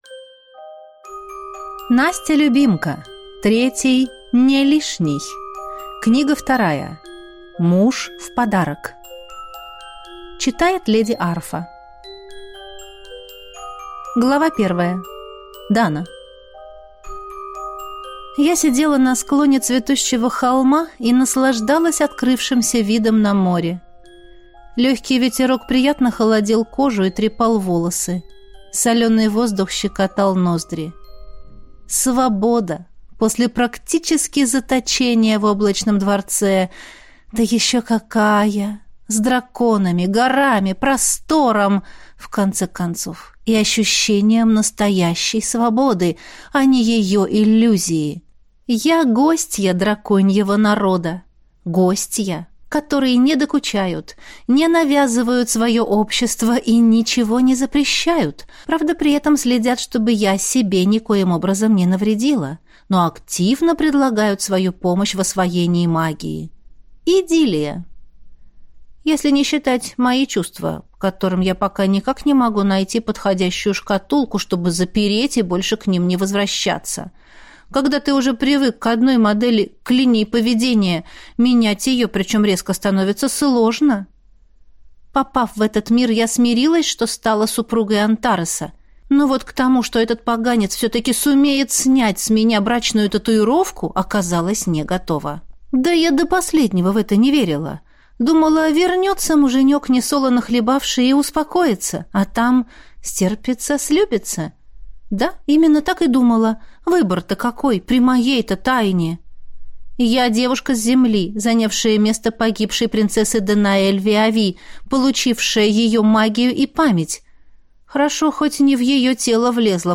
Аудиокнига Муж в подарок | Библиотека аудиокниг